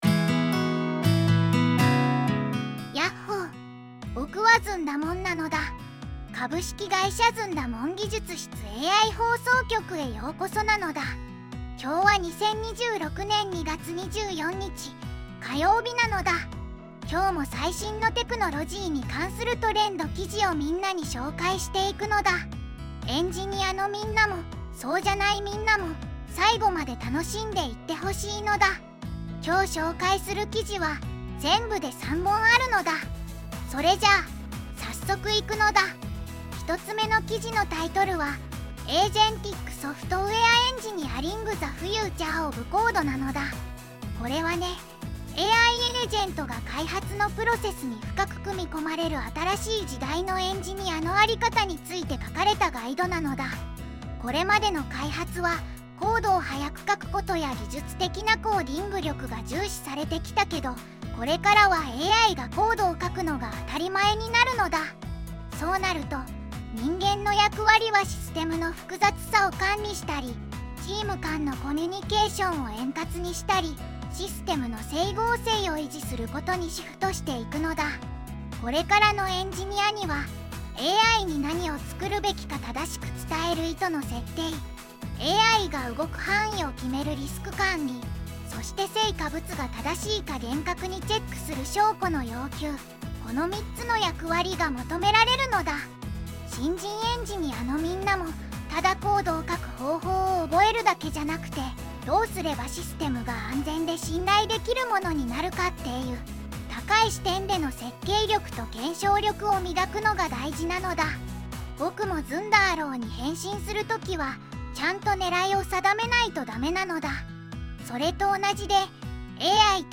ずんだもん